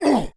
monster / barbarian_knight / damage_1.wav
damage_1.wav